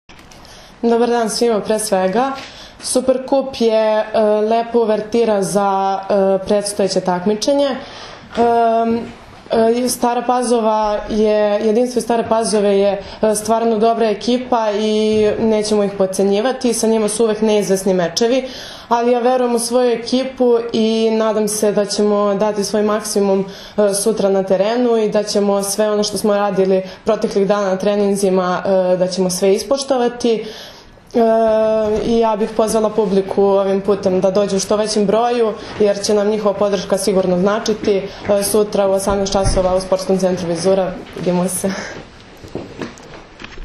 U prostorijama Odbojkaškog saveza Srbije danas je održana konferencija za novinare povodom utakmice IV Super Kupa Srbije 2016. u konkurenciji odbojkašica, koja će se odigrati sutra (četvrtak, 13. oktobar) od 18,00 časova u dvorani SC “Vizura” u Beogradu, između Vizure i Jedinstva iz Stare Pazove, uz direktan prenos na RTS 2.
IZJAVA